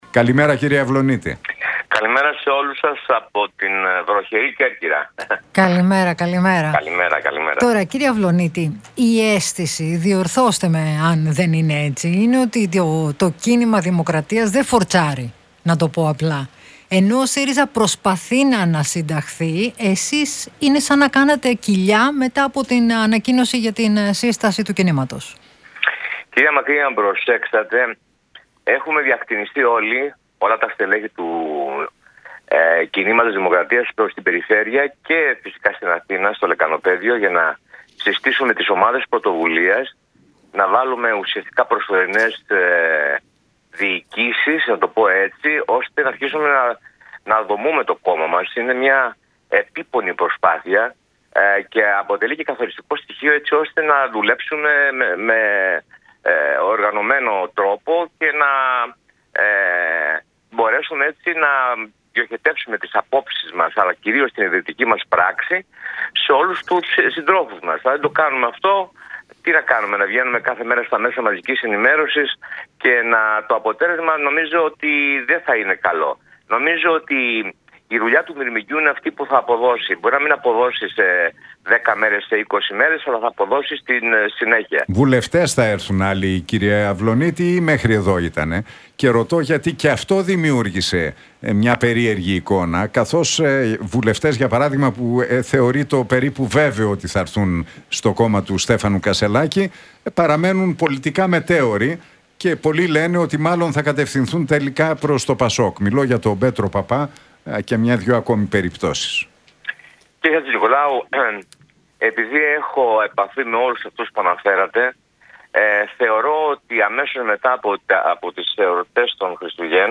Αυλωνίτης στον Realfm 97,8 για το «Κίνημα Δημοκρατίας»: Μετά τα Χριστούγεννα θα έχουμε σχηματίσει και Κοινοβουλευτική Ομάδα